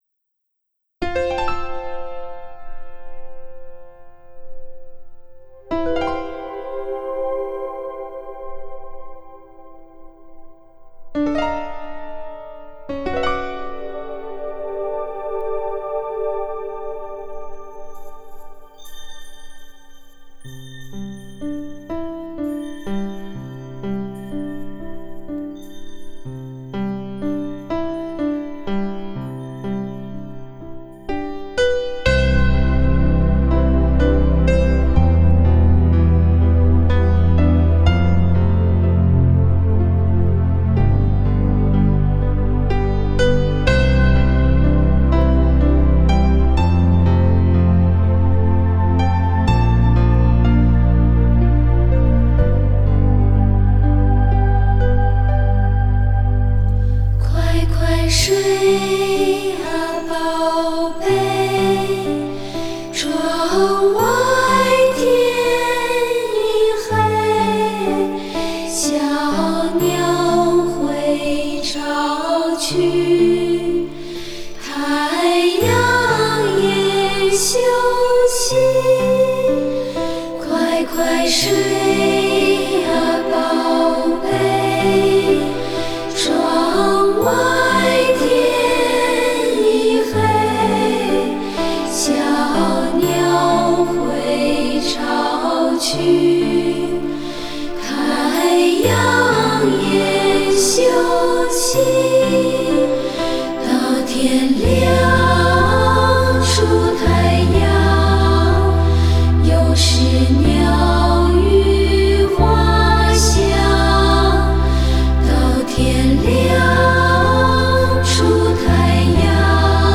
妙曼音符如蜻蜓点水般轻柔掠过！